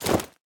Minecraft Version Minecraft Version snapshot Latest Release | Latest Snapshot snapshot / assets / minecraft / sounds / item / armor / equip_wolf1.ogg Compare With Compare With Latest Release | Latest Snapshot
equip_wolf1.ogg